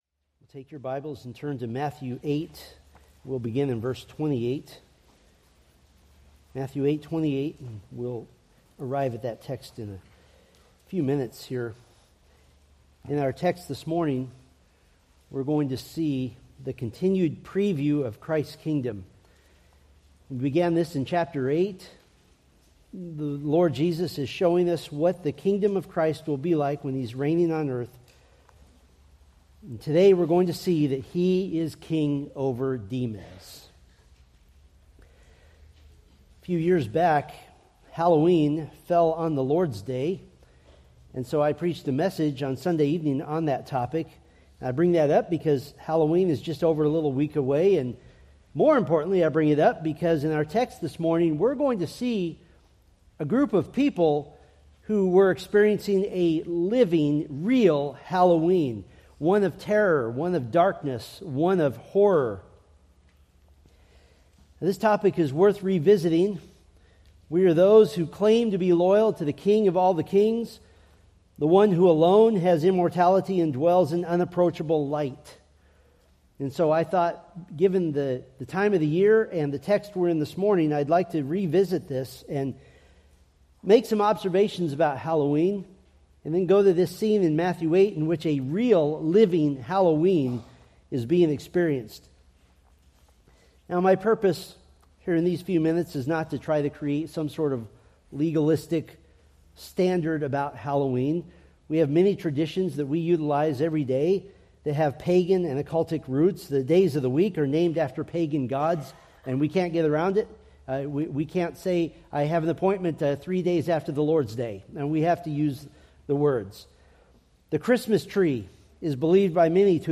From the A Preview of Christ's Kingdom sermon series.
Sermon Details